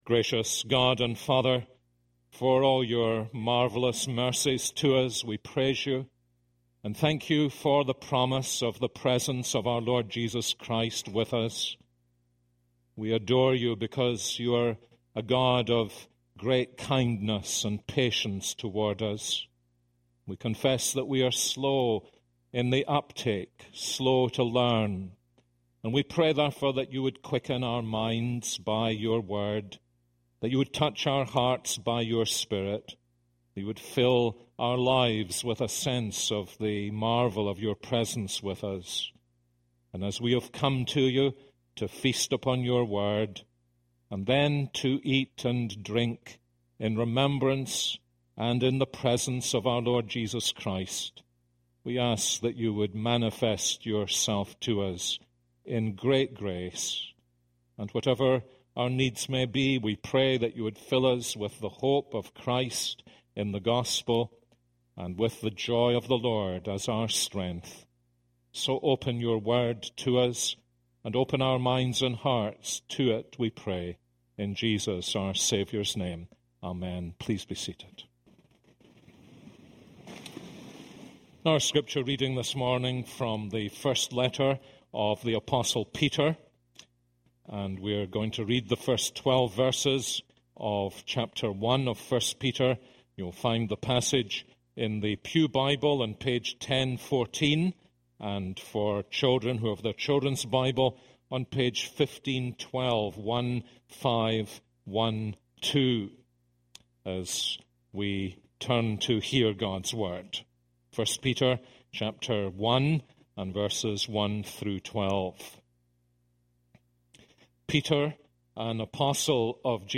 This is a sermon on 1 Peter 1:1-12.